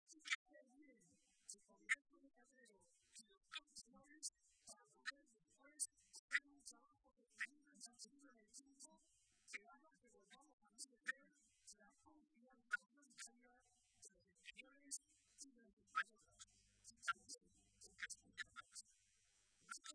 “Hemos trasformado más la sociedad que el propio partido y el partido que ha trasformado la sociedad ahora se tiene también que trasformar para estar a la altura de esa nueva sociedad”, señaló Barreda durante su intervención en la tradicional comida navideña de los socialistas de la provincia de Toledo a la que asistieron más de 1.000 personas.
Intervención de Barreda